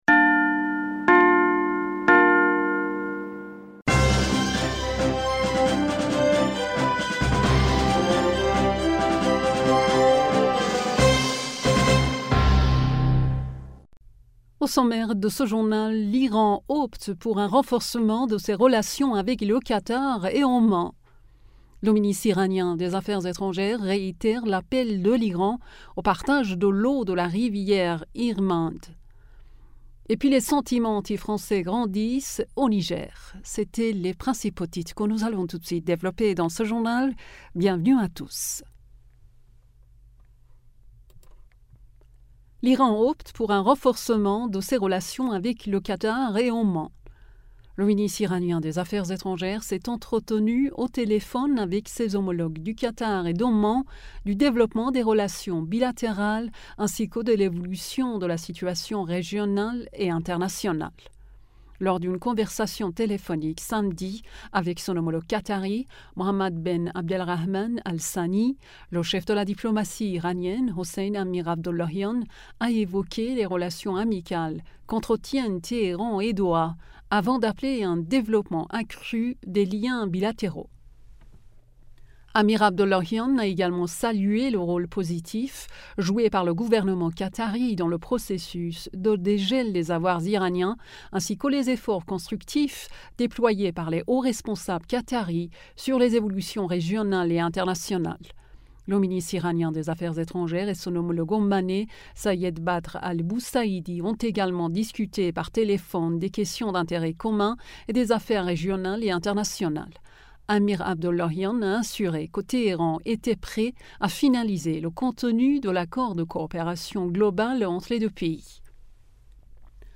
Bulletin d'information du 27 Aout 2023